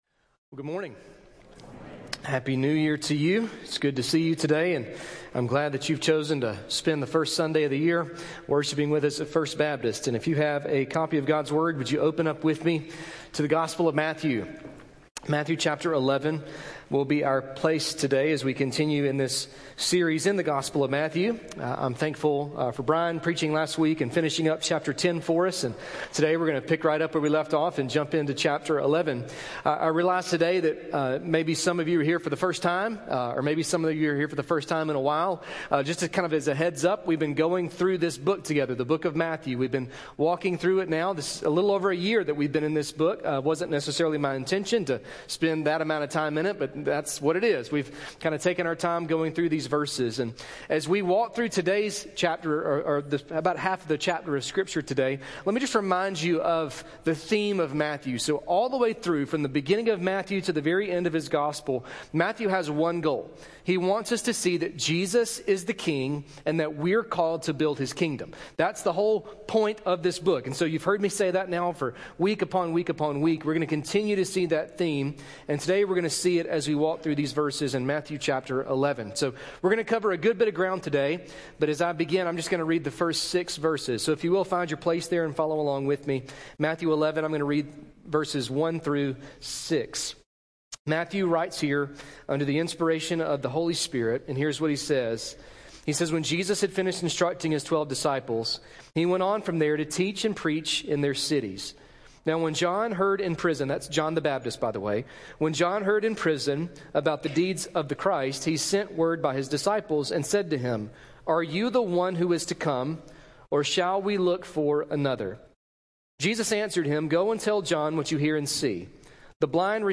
A message from the series "Your Kingdom Come."